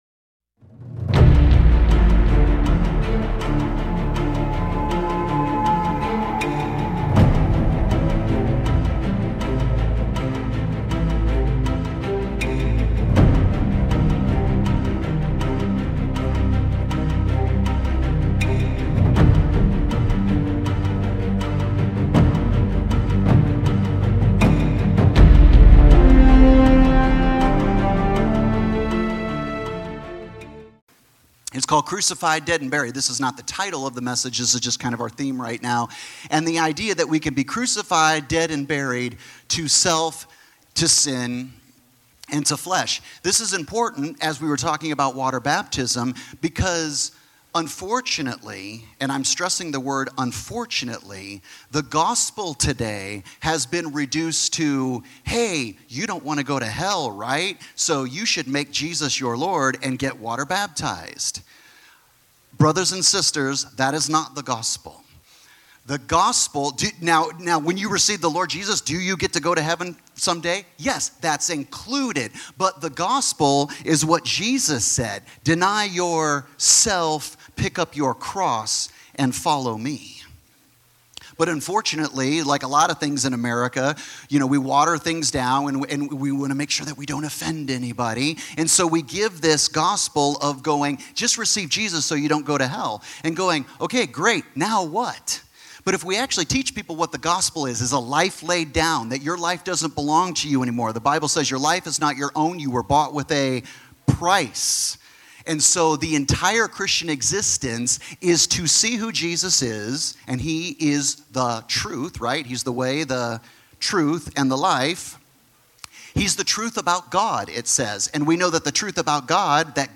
Sermons | 7 Mountains Church